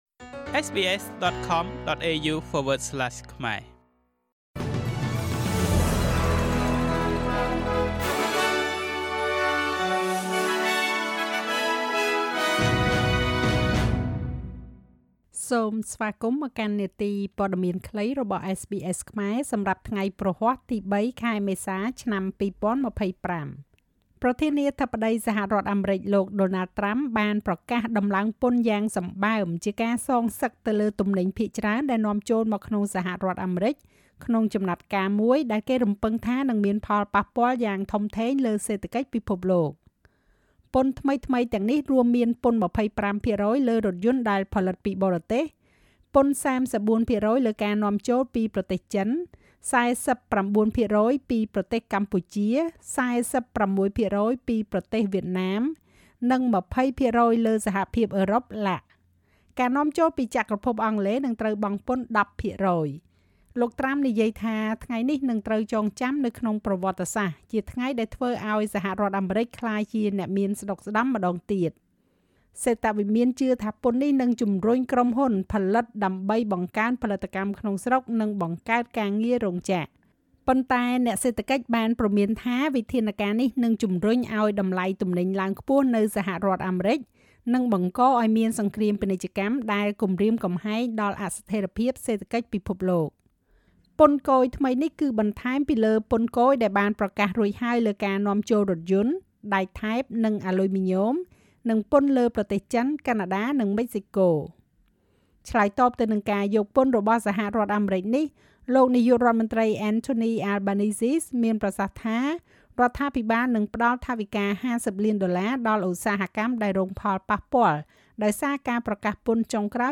នាទីព័ត៌មានខ្លីរបស់SBSខ្មែរ សម្រាប់ថ្ងៃព្រហស្បតិ៍ ទី៣ ខែមេសា ឆ្នាំ២០២៥